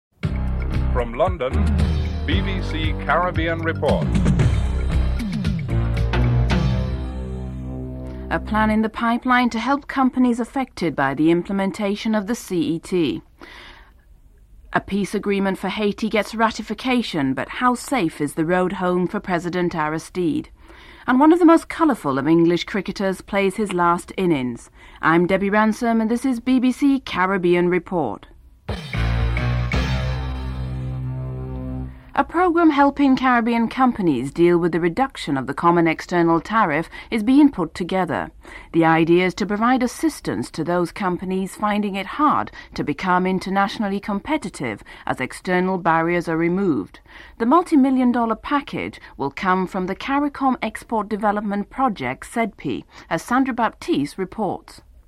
1. Headlines (00:00-00:38)
Interviews with Ian Botham and Viv Richards, friend and former West Indies Cricket Captain (12:15-15:02)